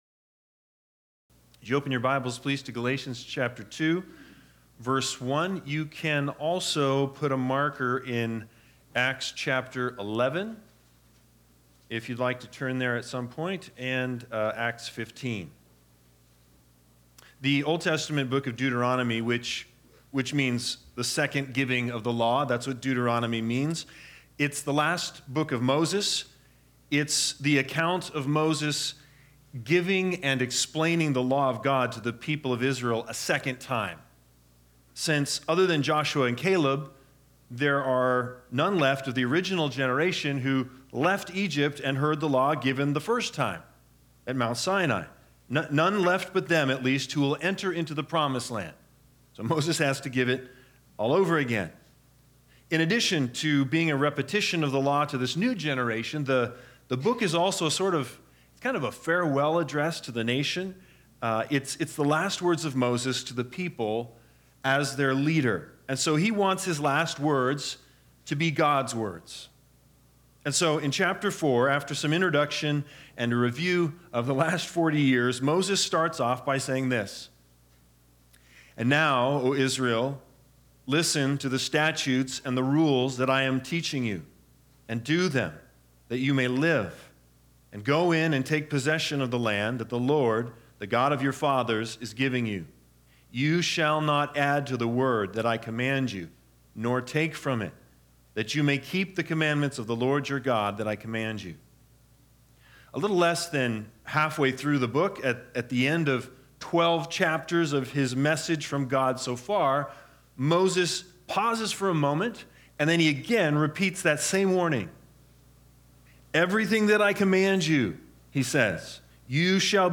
Galatians 2:1-10 Service Type: Sunday Sermons BIG IDEA